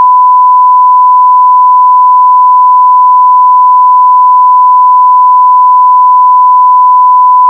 SONAR 3.1 に  1KHz のサイン波を読み込み、それぞれ各周波数に変換した後の波形を見てみました。